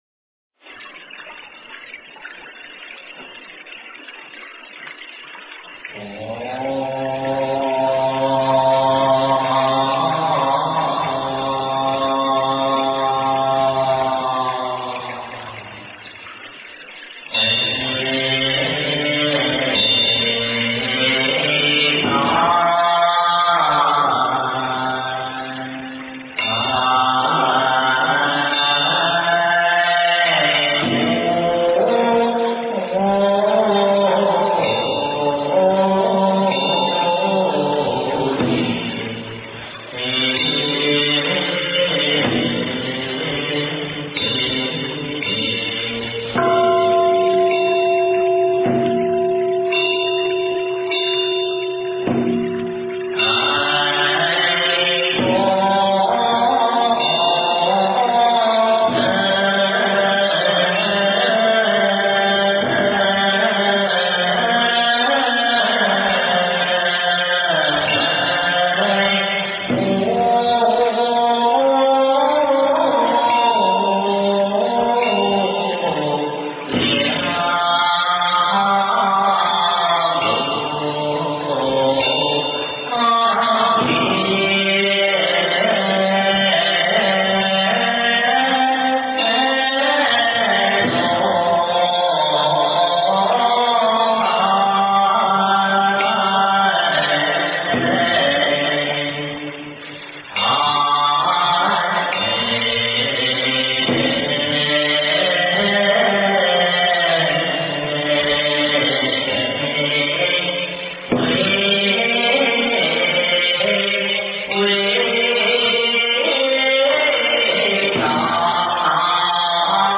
经忏
佛音 经忏 佛教音乐 返回列表 上一篇： 普佛(代晚课